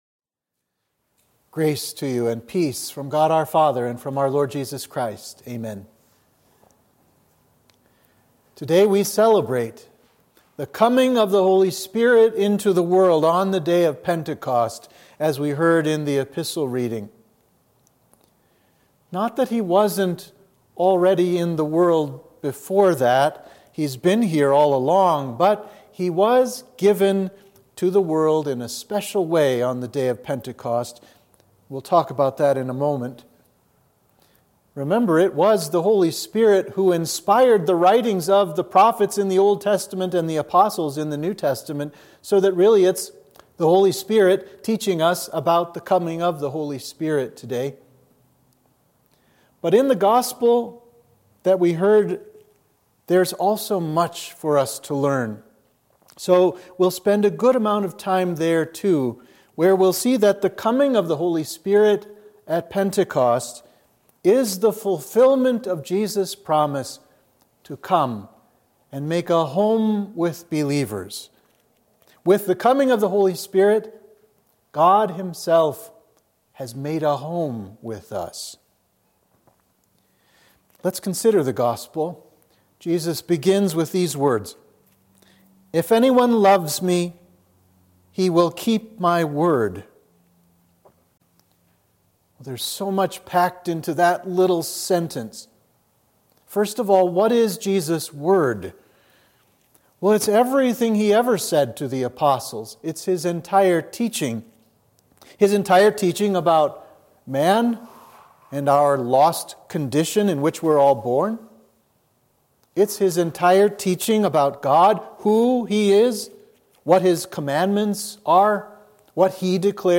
Sermon for Pentecost